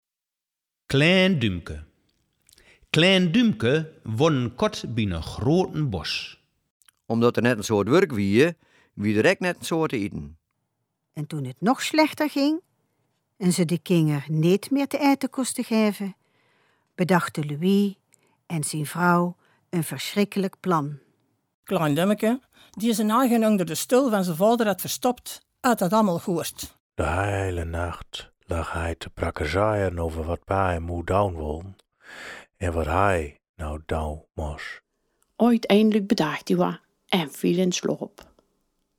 Een serie over sprekers van dialecten en streektalen.
Trailer Dat is andere taal.mp3